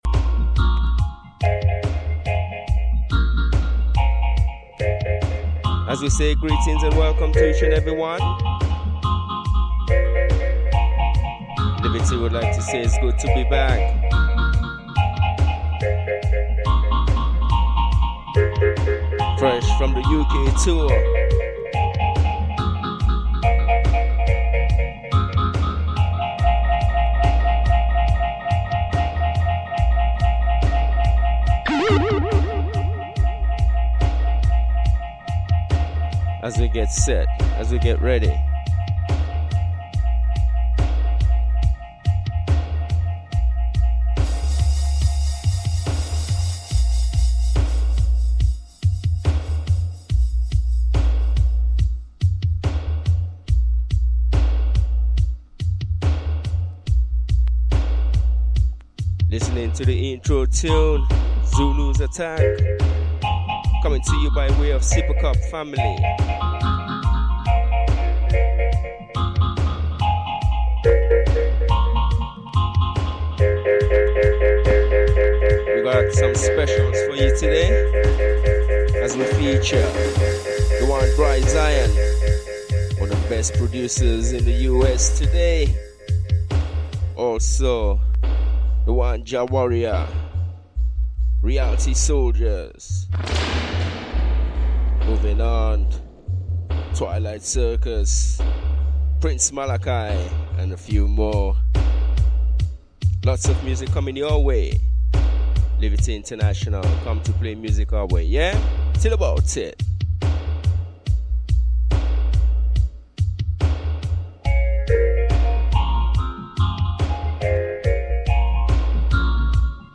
Live & Direct.